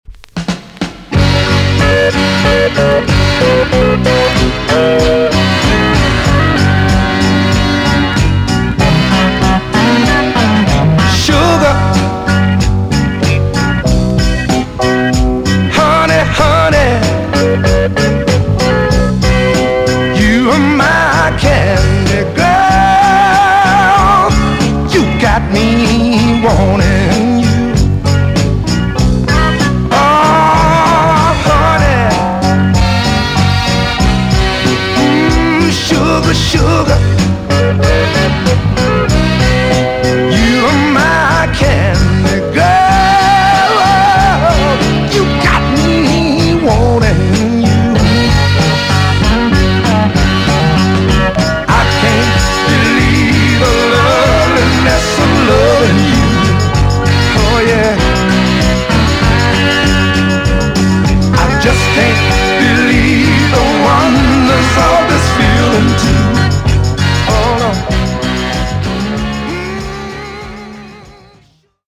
EX 音はキレイです。
NICE NORTHERN SOUL TUNE!!